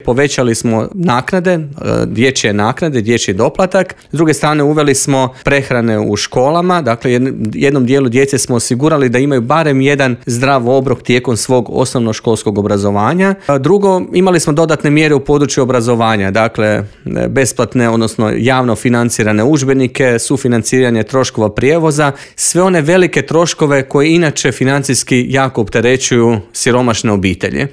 Prate li to socijalne mjere i može li se siromaštvo prevenirati - neke su od teme o kojima smo u Intervjuu Media servisa razgovarali